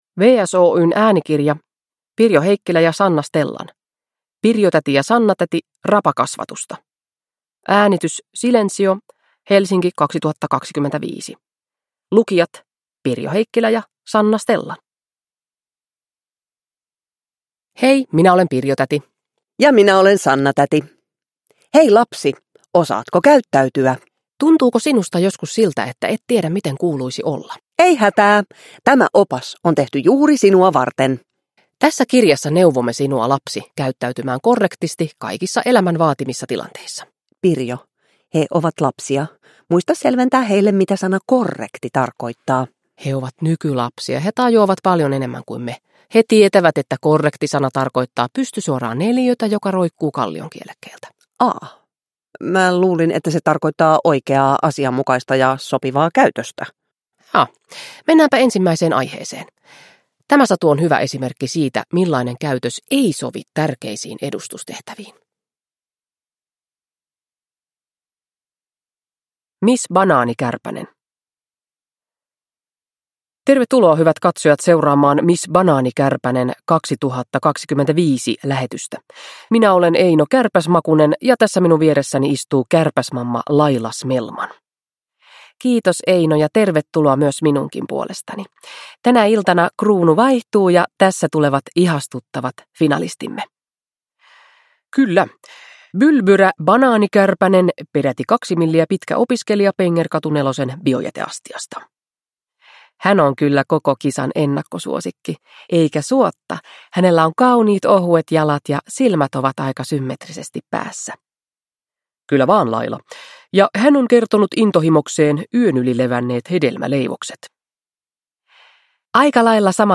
Pirjo-täti ja Sanna-täti: Rapakasvatusta (ljudbok) av Pirjo Heikkilä